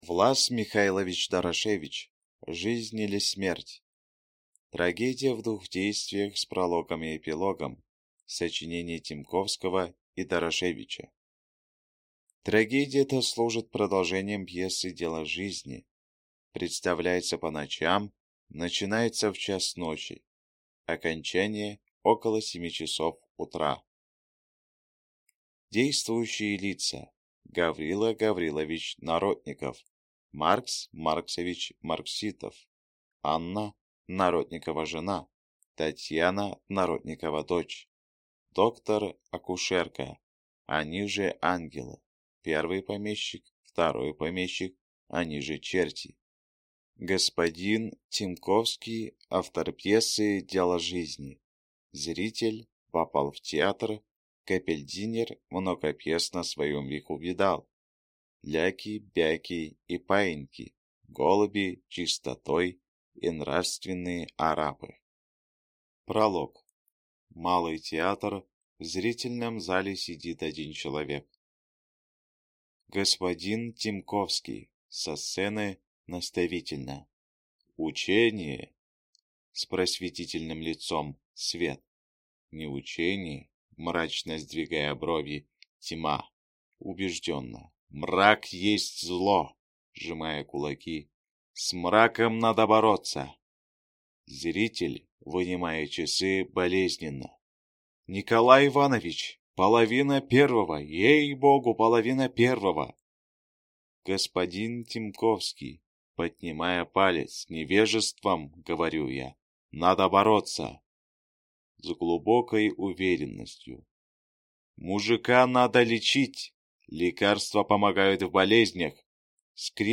Аудиокнига Жизнь или смерть!